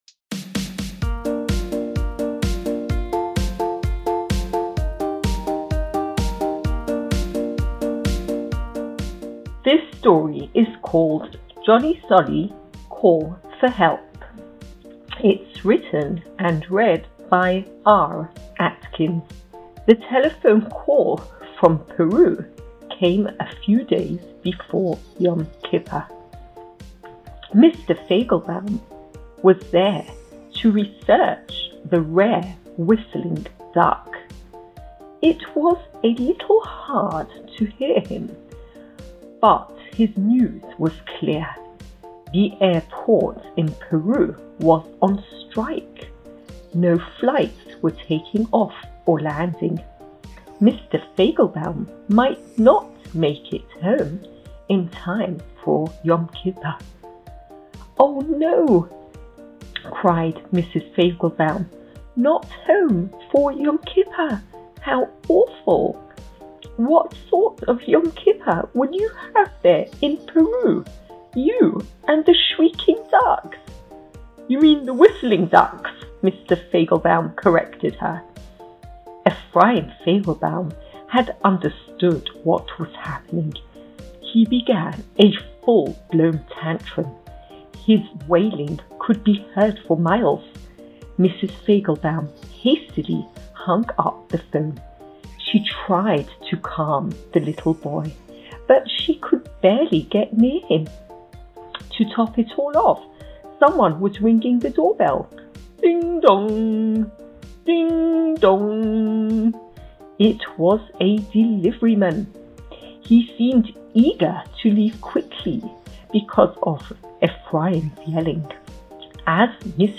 | Read-Along Storytime |